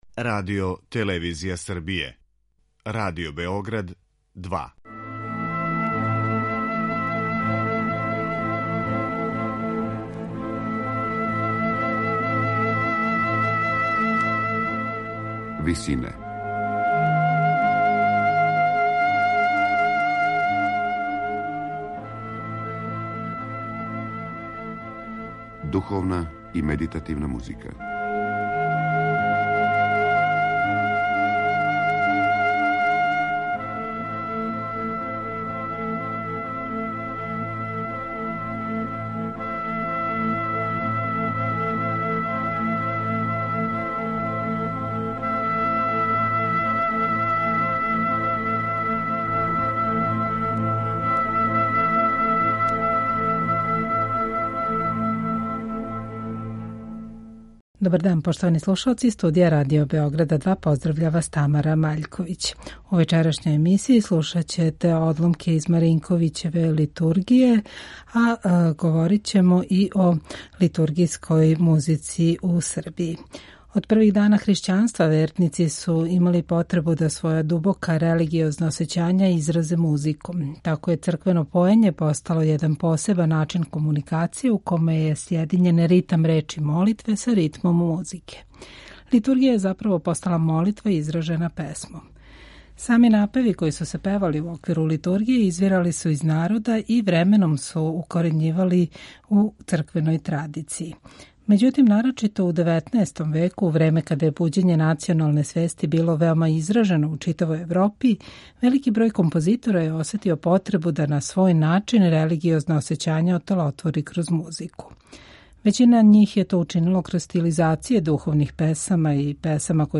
У емисији Висинe емитујемо одломак из Литургије за мешовити хор Јосифа Маринковића у извођењу Хора Радио телевизије Србије под управом Бојана Суђића.